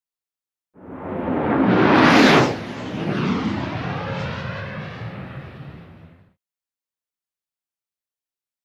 F-14 Tomcat | Sneak On The Lot
Jet; Fly By; Tom Cat Jet Fighter Passing Up And Overhead Three Passes.